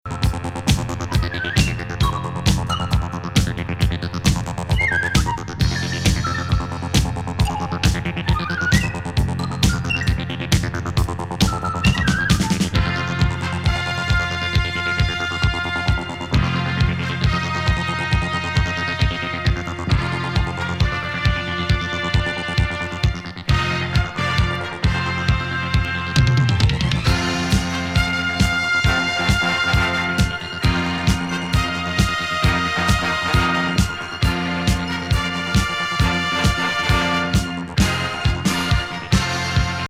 スペース・カナディアン・ディスコ。
垢抜けないイナタサが通好み。